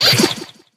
squeak_atk_01.ogg